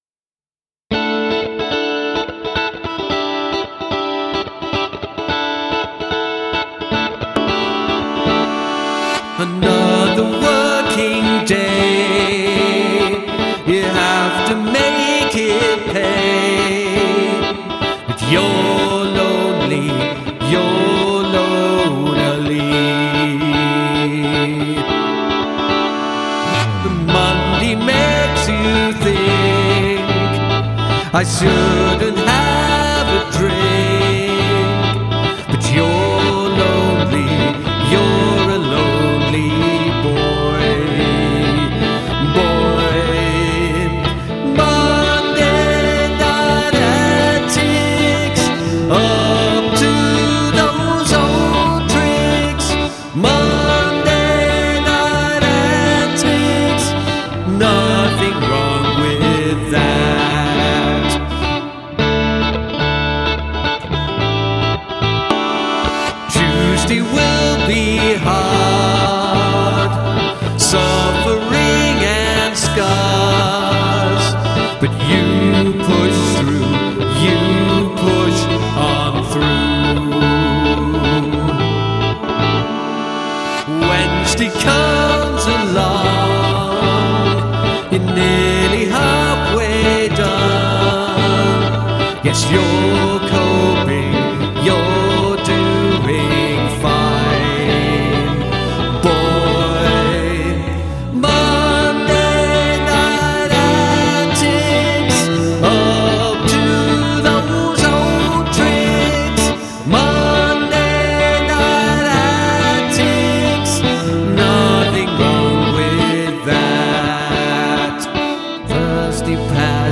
The adding of layers and elements is great :)
It's got a proper singalong chorus, a bit of a Cure vibe.